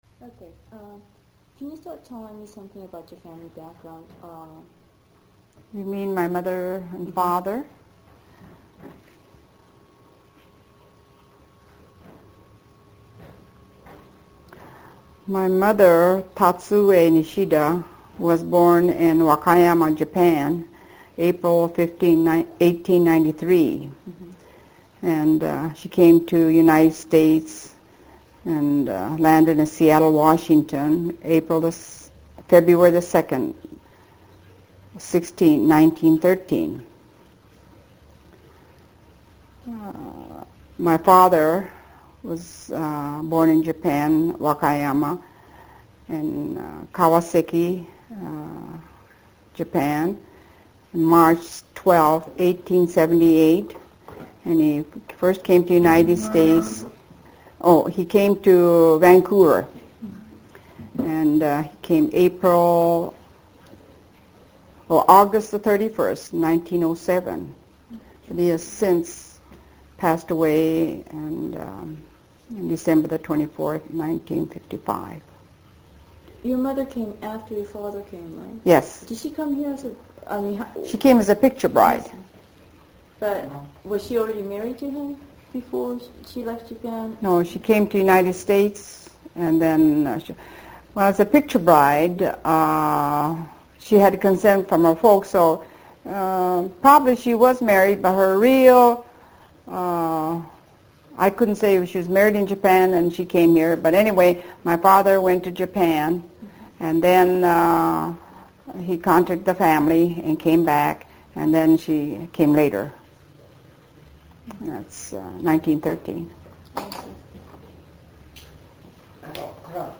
INTERVIEW DESCRIPTION